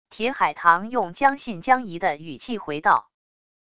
铁海棠用将信将疑的语气回道wav音频